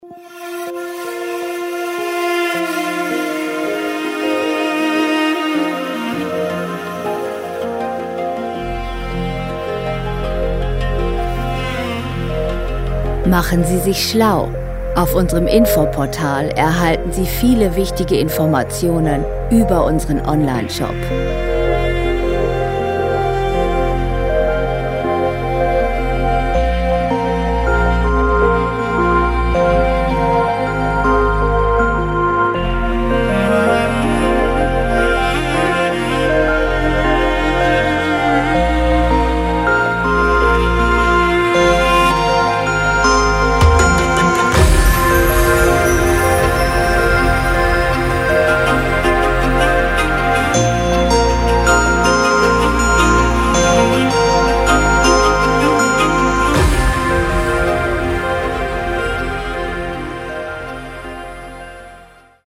• Cinematic Ambient